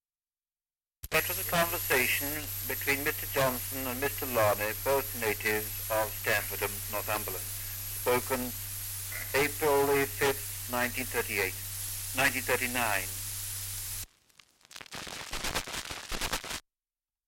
Dialect recording in Stamfordham, Northumberland
78 r.p.m., cellulose nitrate on aluminium